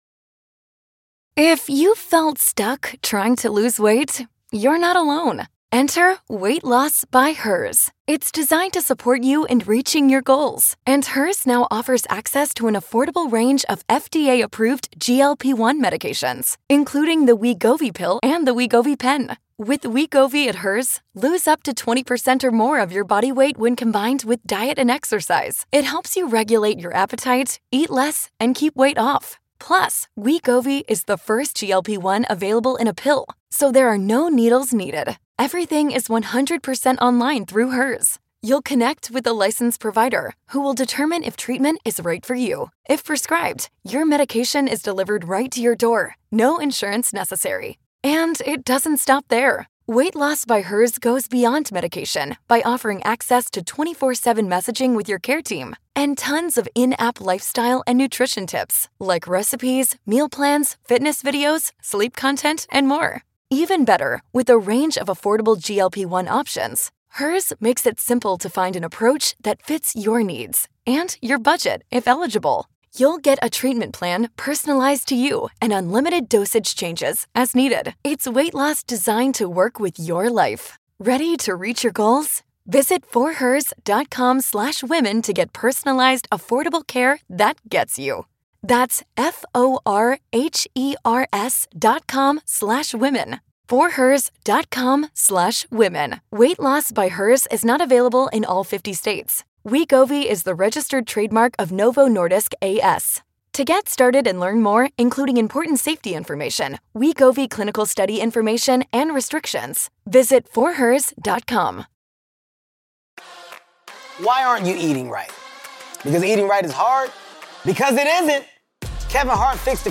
Whether she's spilling details of her time on The Real Housewives of Orange County or opening up about her humble upbringing in Missouri, Elizabeth Vargas, doesn't mince words, which makes for a very fun interview!